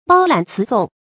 包揽词讼 bāo lǎn cí sòng
包揽词讼发音